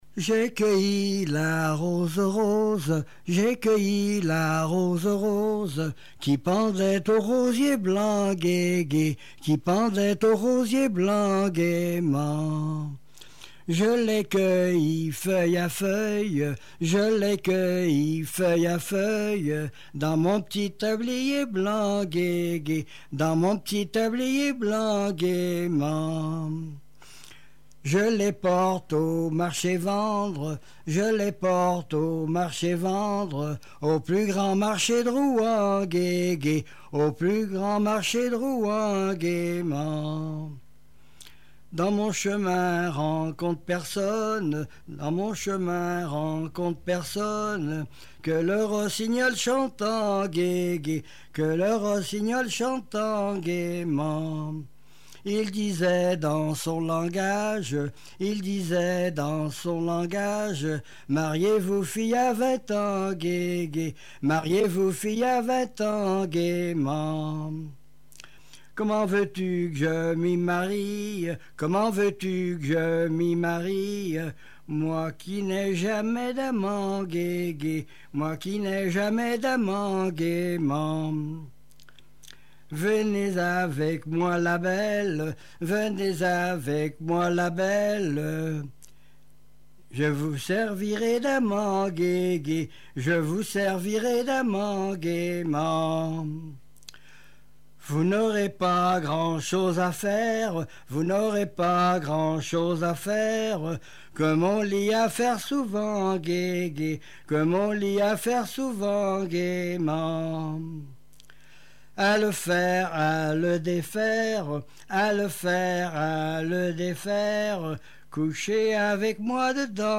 gestuel : à marcher
Genre laisse
Chansons et témoignages
Pièce musicale inédite